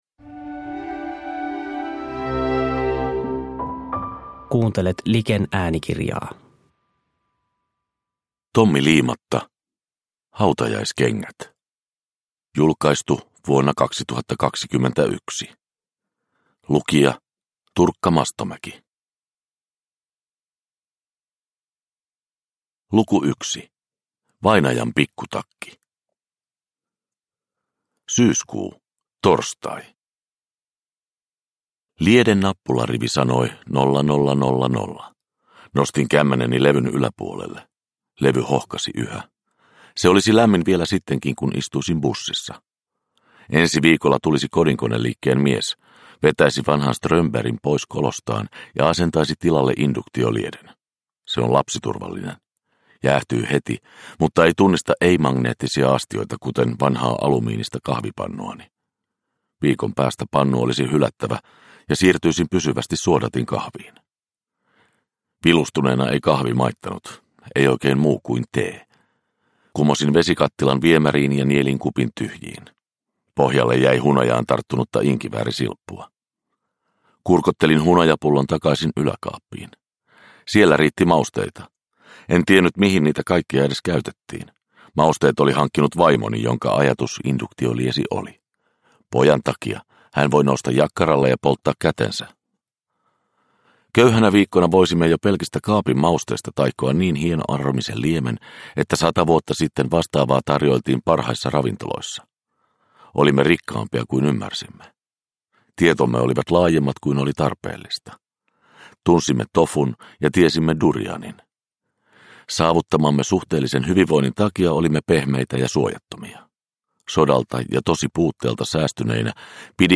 Hautajaiskengät – Ljudbok – Laddas ner